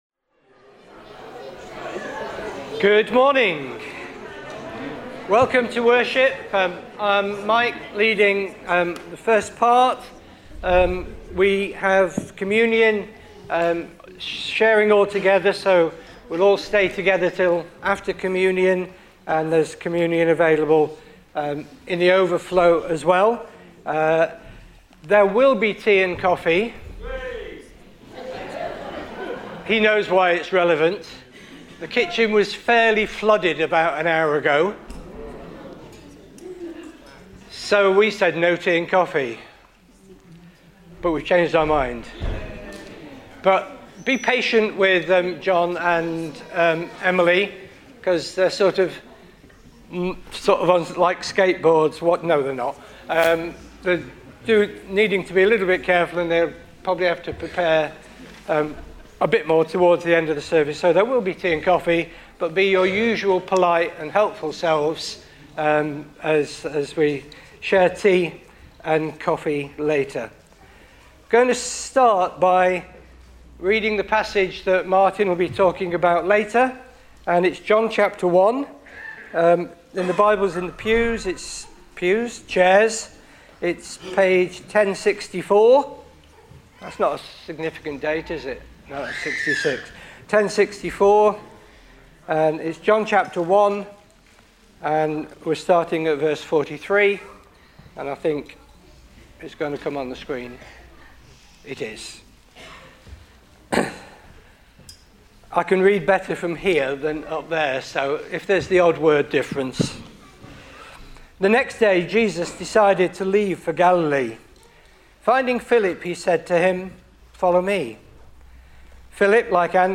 18 January 2026 – Morning Communion Service